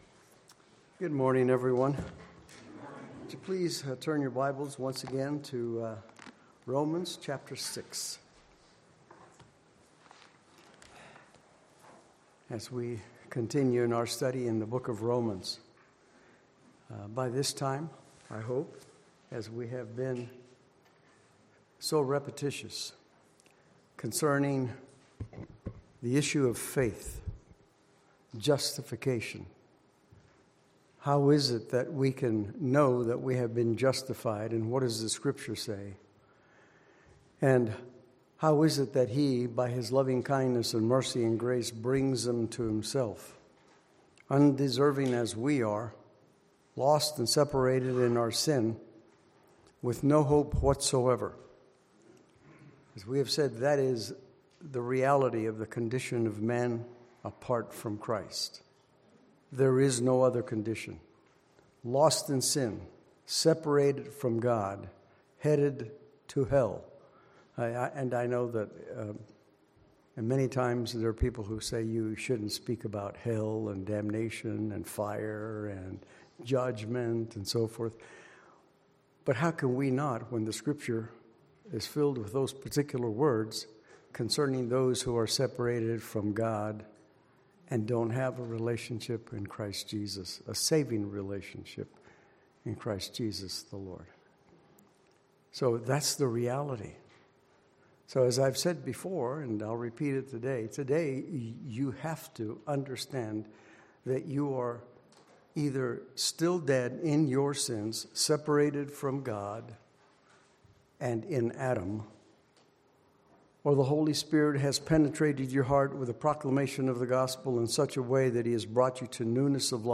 Passage: Romans 6:1-7 Service Type: Sunday Morning Worship « Where Sin Abounded